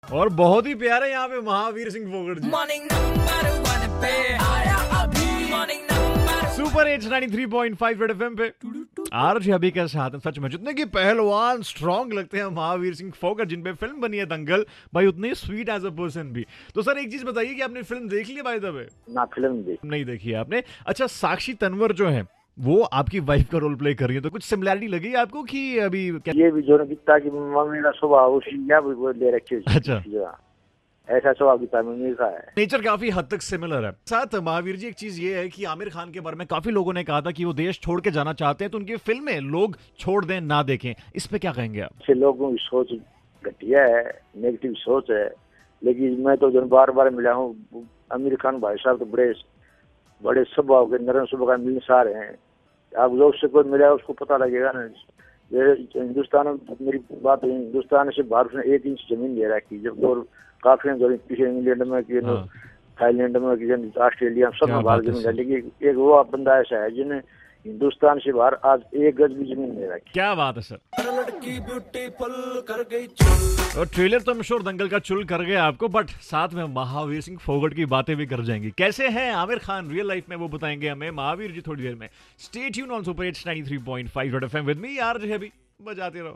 Mahavir Singh Phokat - Real Life Dangal in concersation